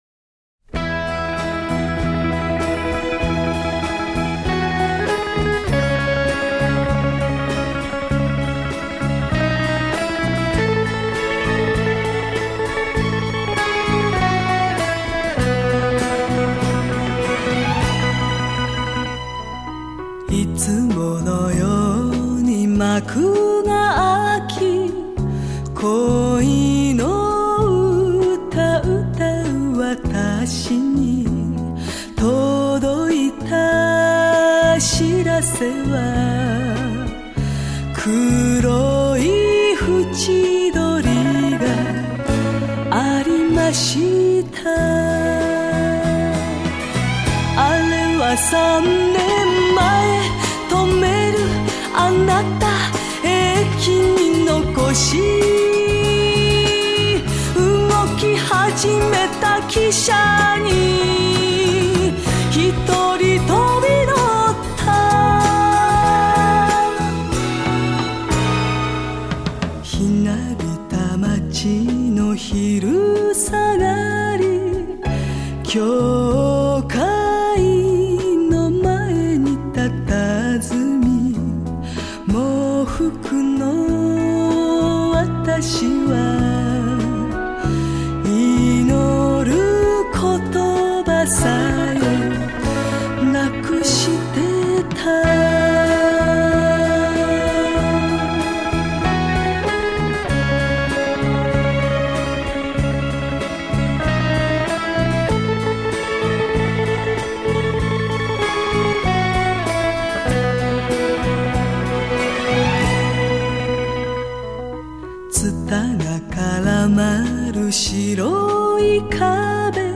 原唱版
不急不缓，娓娓道来。